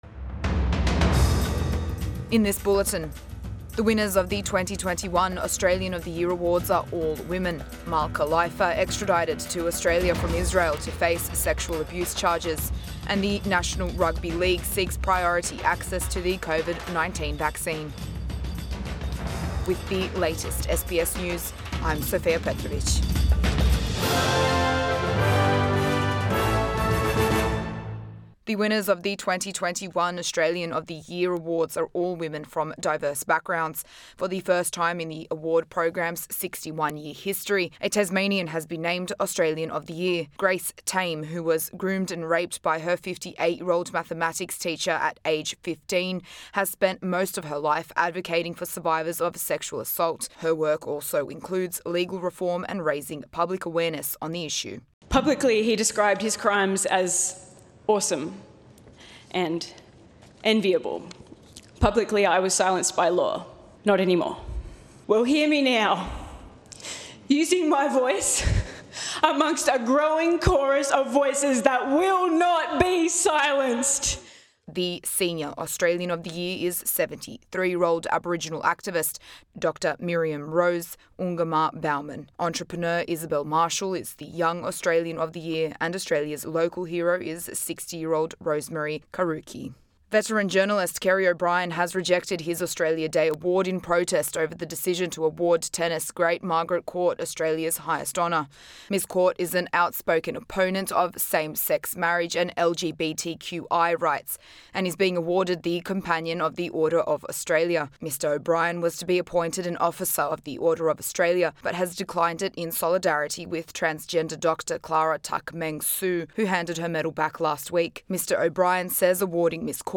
AM bulletin 26 January 2021